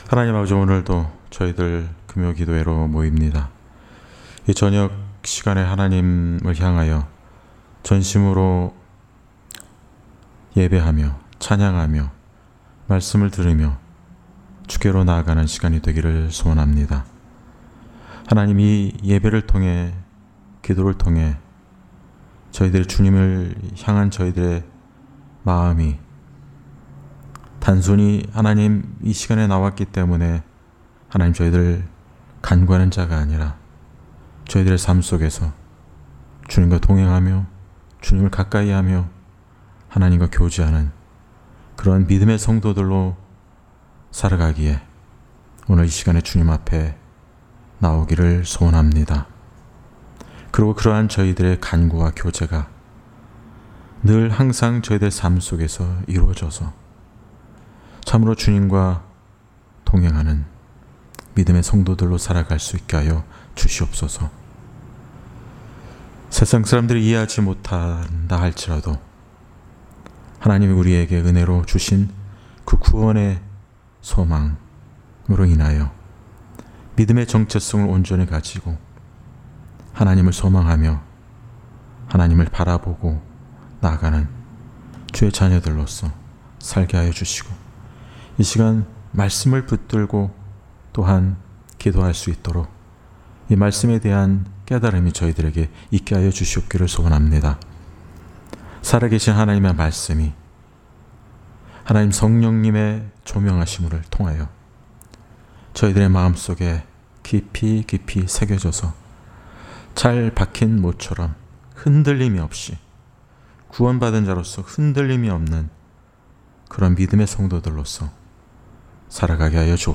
Series: 금요기도회